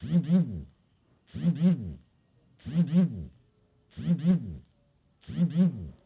Звук в беззвучном режиме вибрирует в кармане